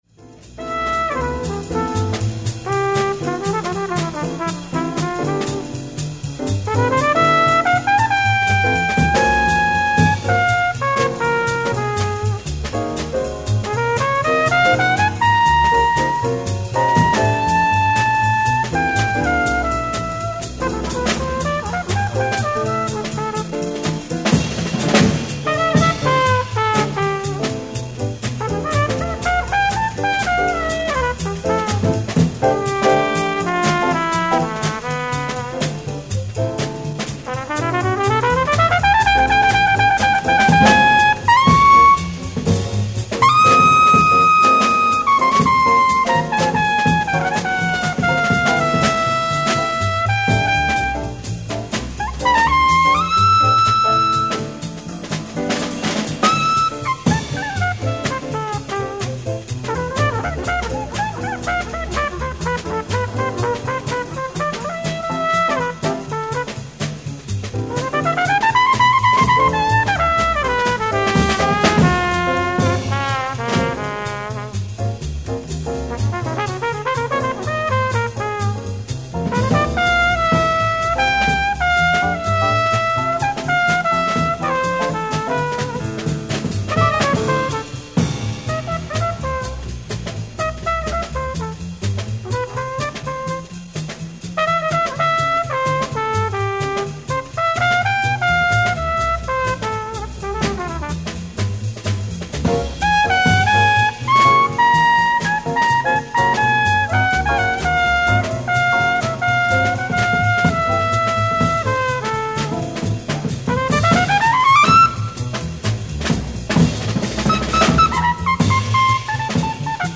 Disc 1(Early Concert)